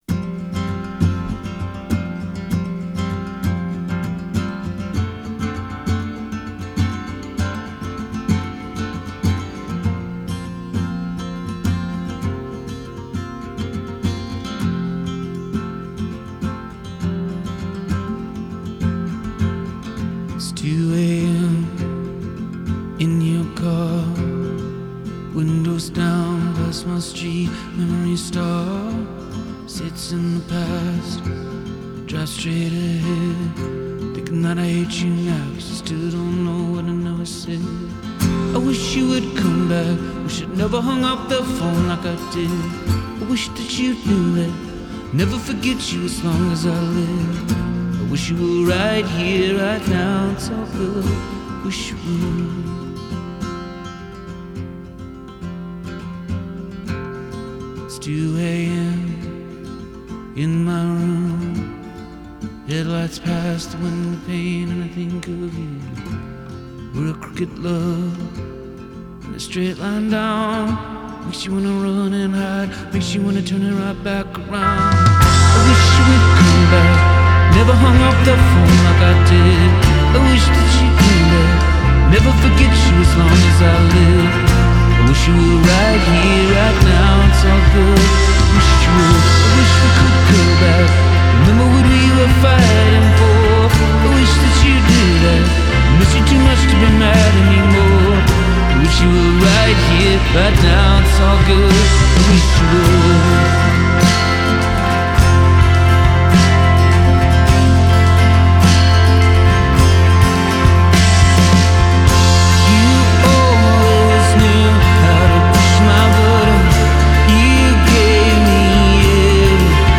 Genre: Rock, Folk, Pop, Covers